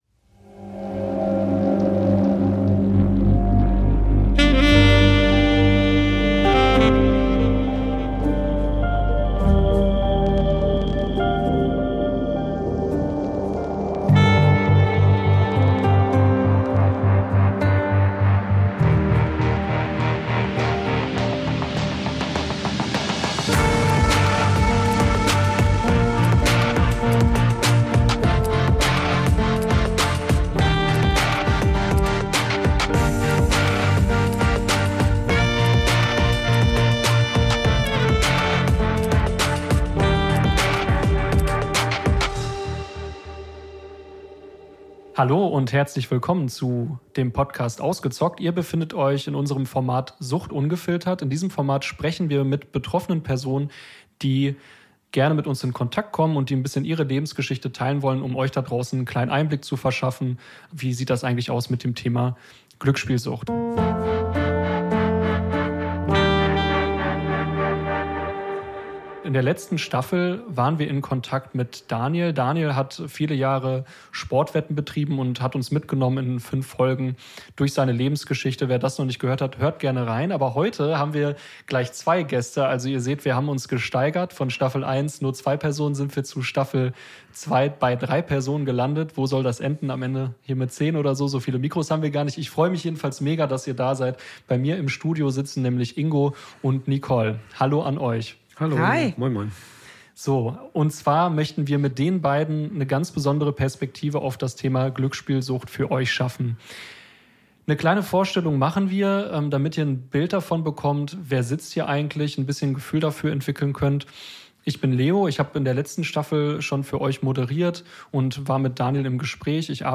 Folge 1: Die Anfänge ~ Ausgezockt: Sucht ungefiltert - Betroffene im Gespräch Podcast